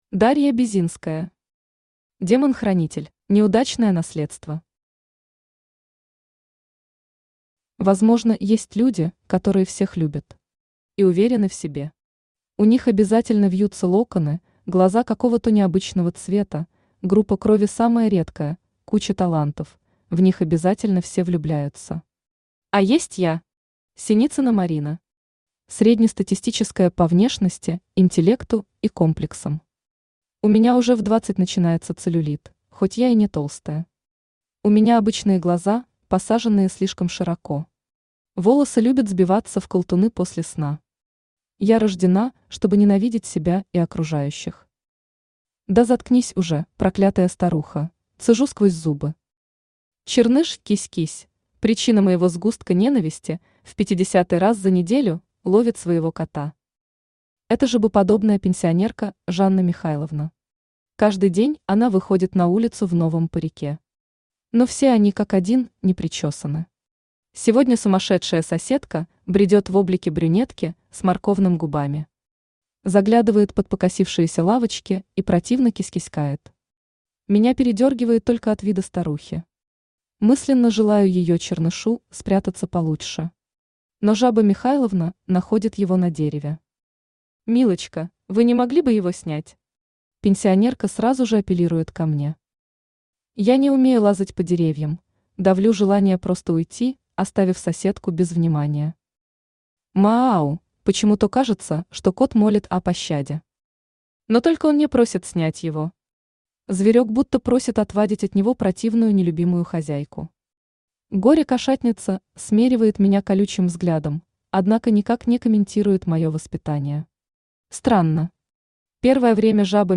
Читает: Авточтец ЛитРес
Аудиокнига «Демон-хранитель».